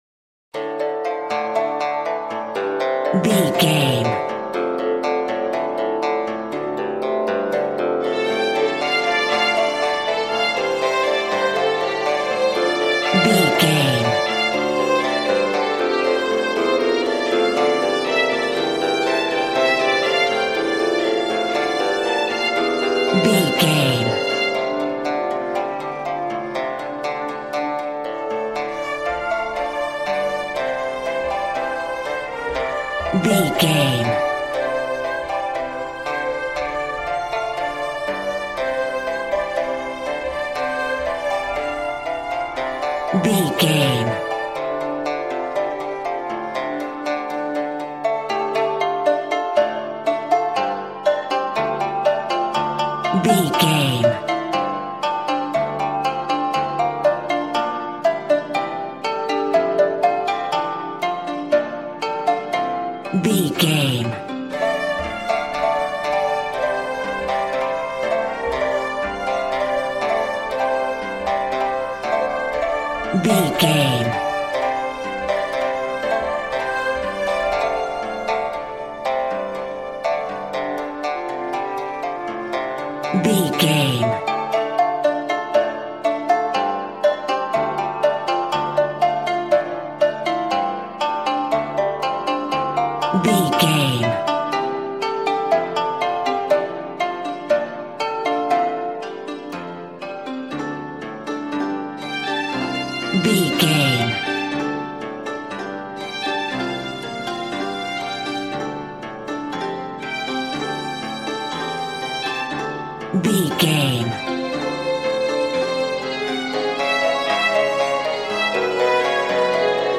Aeolian/Minor
smooth
conga
drums